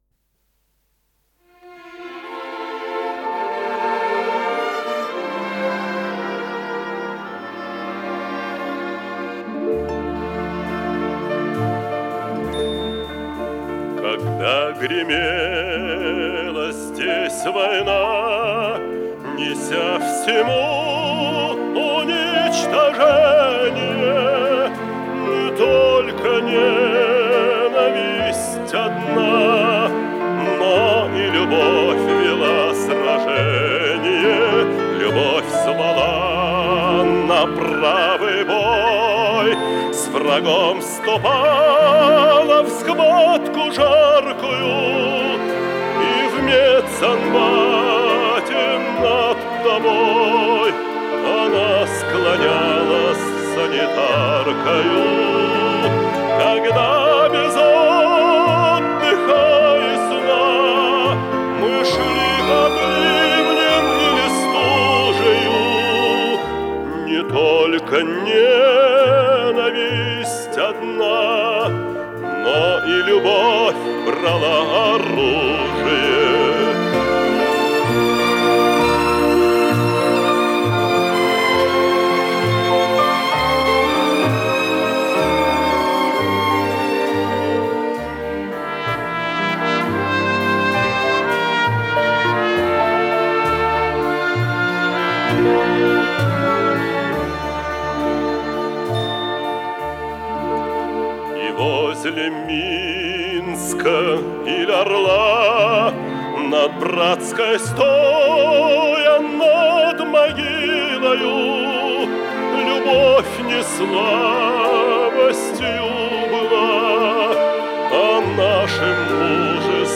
с профессиональной магнитной ленты
ВариантДубль стерео